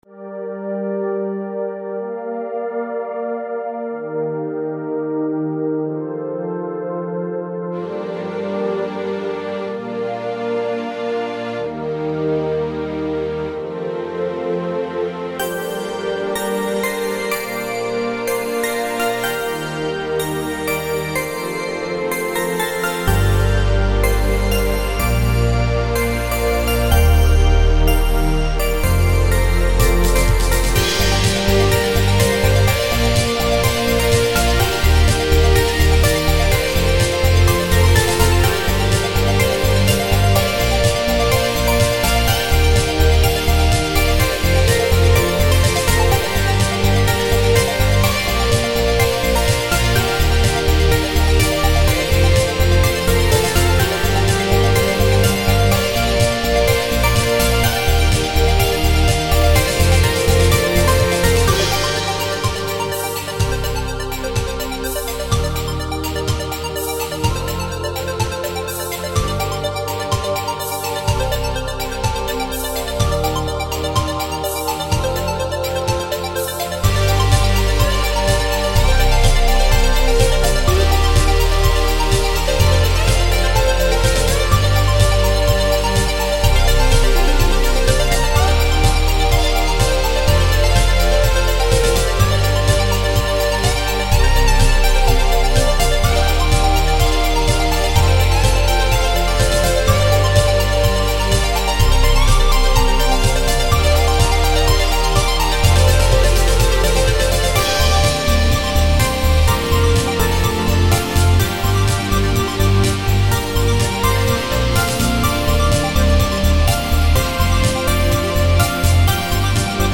Artist Made for -an adventurous electronica track!